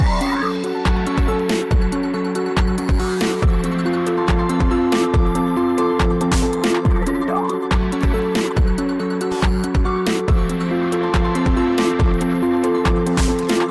Подскажите как создать такой эффект как в аудио файле для сольной мелодии. Насколько я понимаю там задействован арпеджиатор, но есть еще некоторые еффекты в конце каждой фразы (типа перегруза что ли).